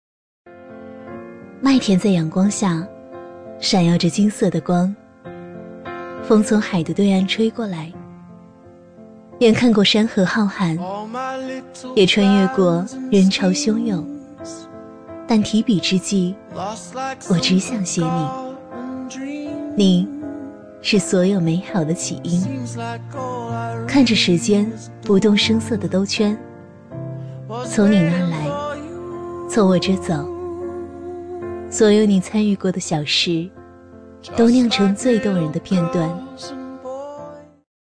A类女50
【女50号旁白】微电影-自然-婚礼
【女50号旁白】微电影-自然-婚礼.mp3